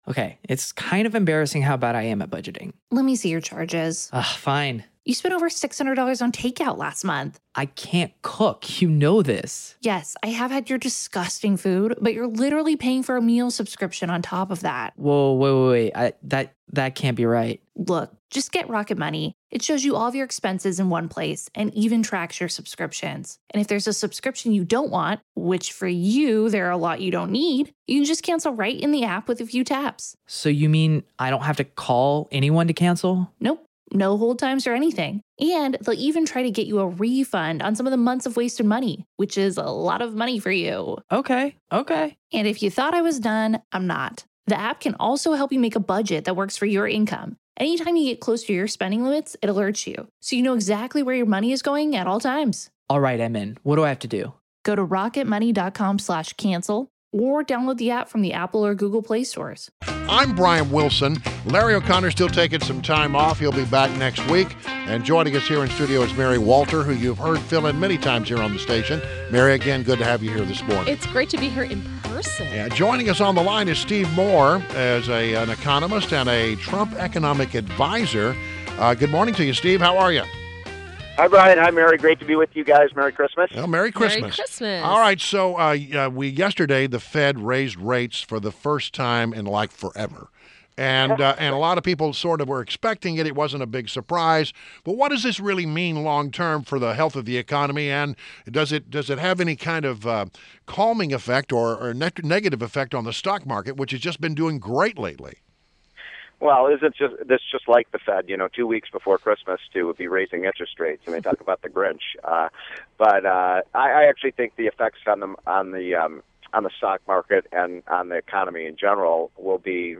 WMAL Interview - STEVE MOORE - 12.15.16
INTERVIEW — STEVE MOORE — Economist and a Trump economic adviser